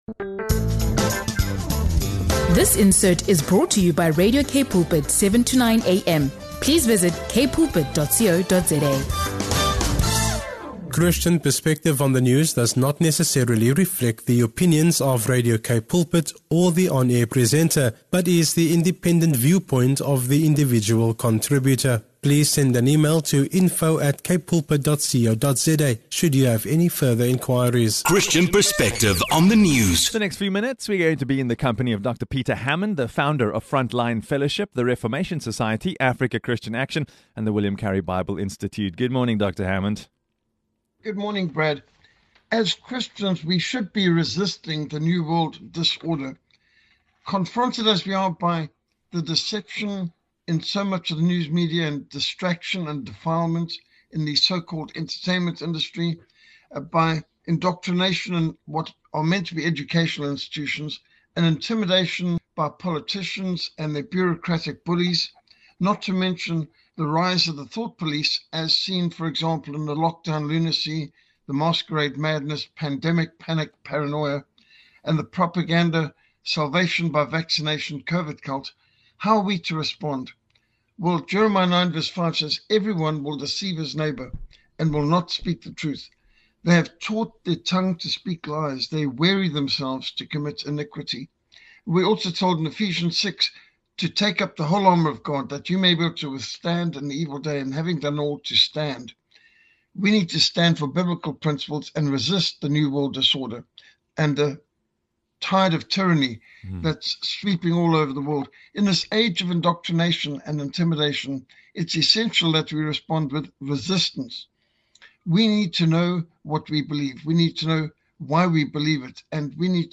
In this fiery podcast episode